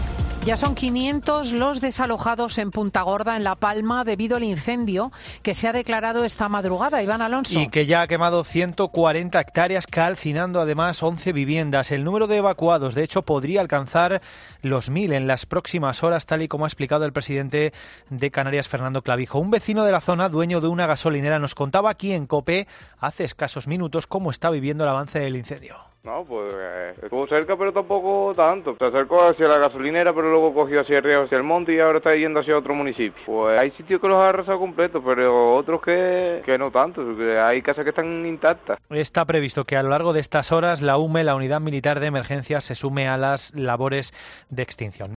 “Hay sitios que los ha arrasado completo", relata un vecino de la zona afectada por los incendios en Canarias